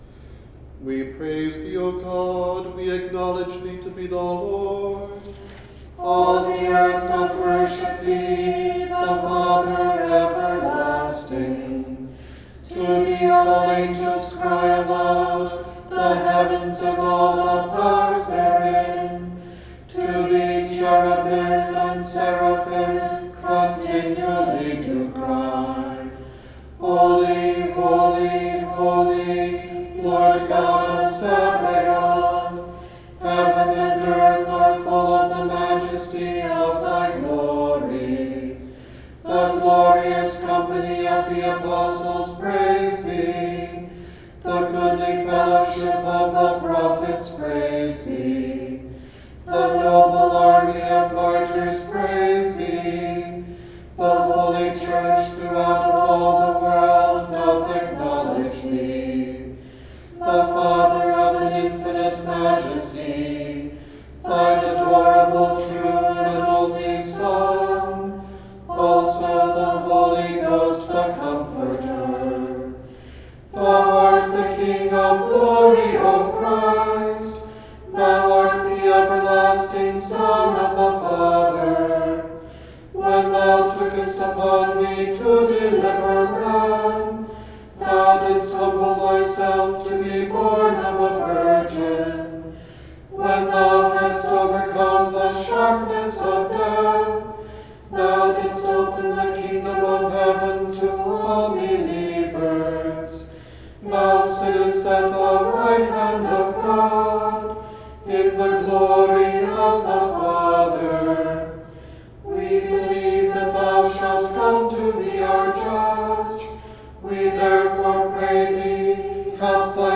A few selections recorded by our choir (pieces marked with an * are from a CD recorded by singers from several Western Rite parishes).
Music for the Daily Office